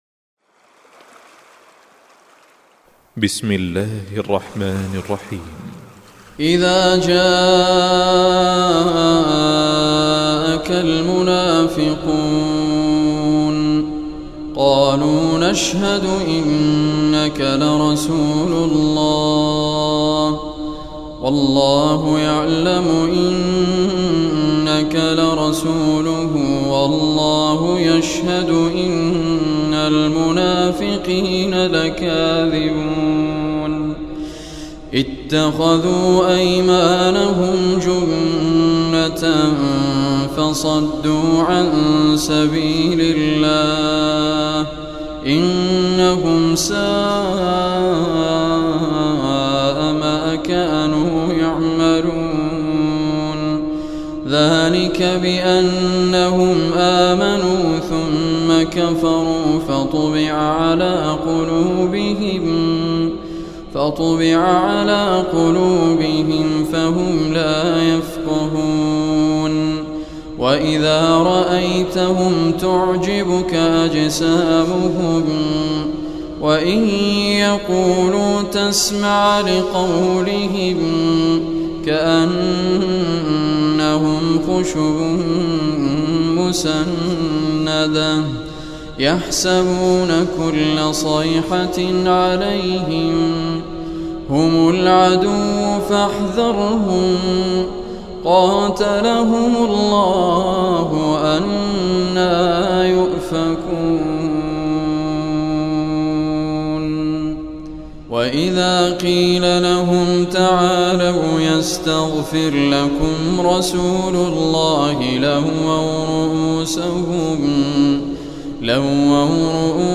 Surah Munafiqun Recitation by Muhammad Raad Kurdi
Surah Munafiqun, is 63th chapter of Holy Quran. Listen or play online mp3 tilawat / recitation in Arabic in the beautiful voice of Sheikh Muhammad Raad Al Kurdi.